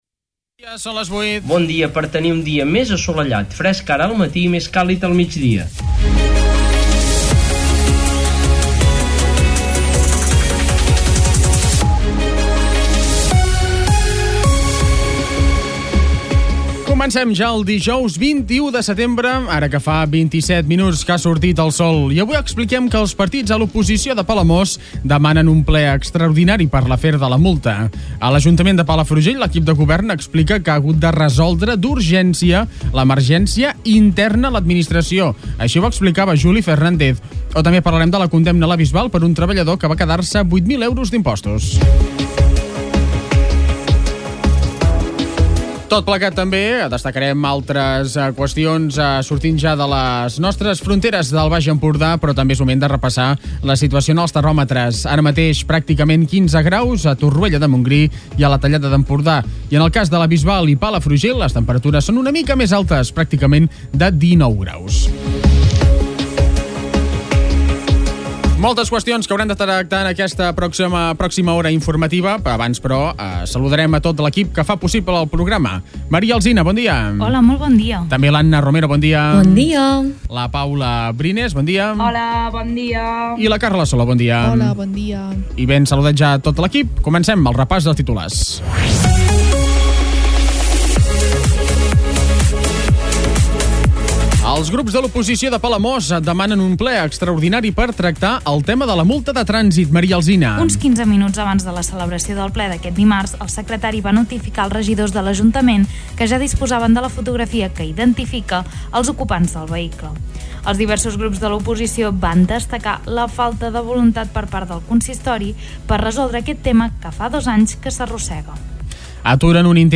Escolta l'informatiu d'aquest dijous